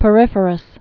(pə-rĭfər-əs)